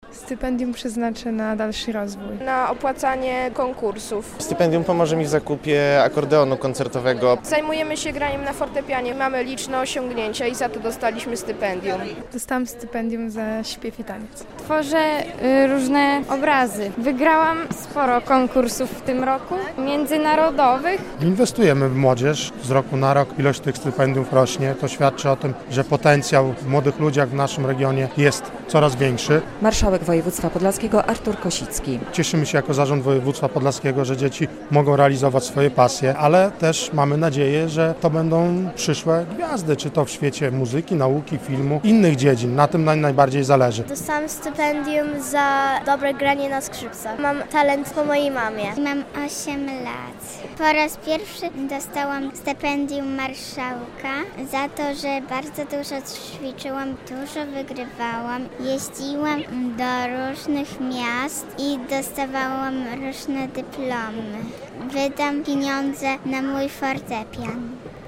relacja
510 młodych ludzi uhonorowano podczas uroczystej gali w Operze i Filharmonii Podlaskiej.